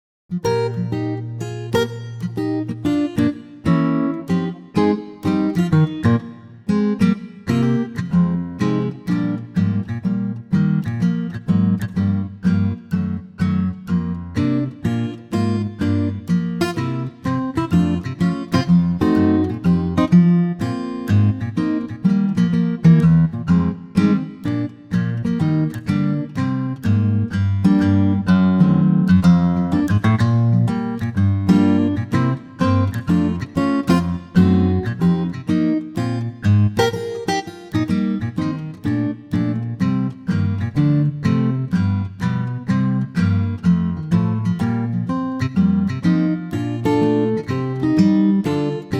Newly added backing track downloads for Male ranges.
key - Eb - vocal range - Bb to D